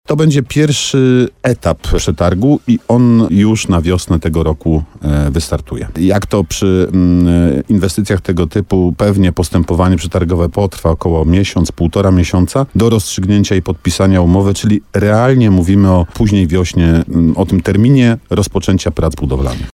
– Na razie prace mają dotyczyć skrzyżowania ulic Długosza i Kościuszki, a także wyprostowania ulicy Sobieskiego – mówił wiceprezydent Nowego Sącza Artur Bochenek w programie Słowo za Słowo na antenie RDN Nowy Sącz.